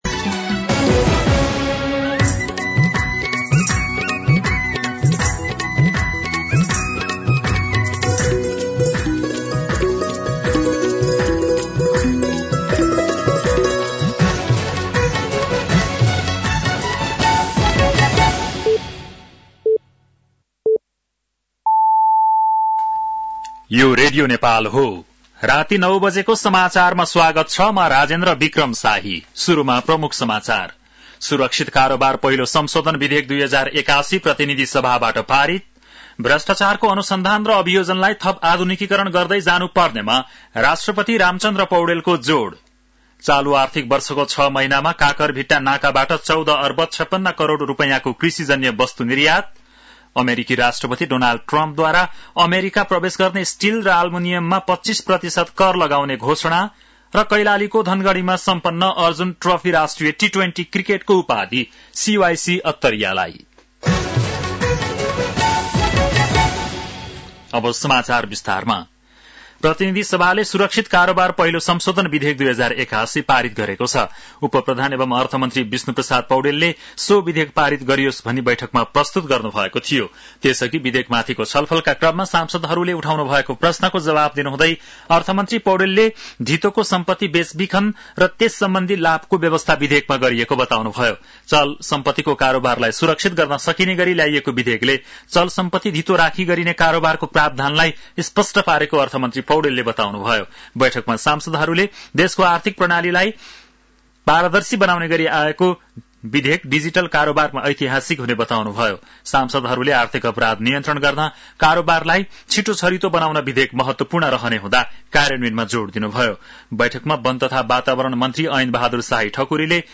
बेलुकी ९ बजेको नेपाली समाचार : २९ माघ , २०८१
9-PM-Nepali-News-10-29.mp3